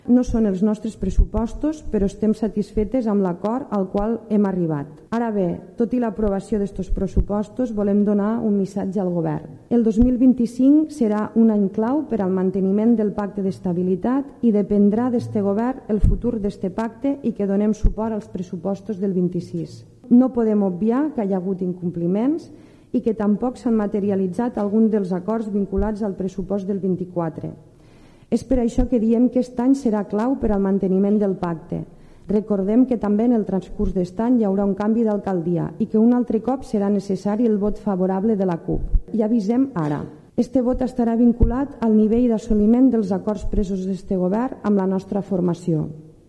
El Govern de Movem-PSC i ERC ha aprovat amb el suport de la CUP el pressupost per al 2025, en un ple extraordinari celebrat aquest divendres.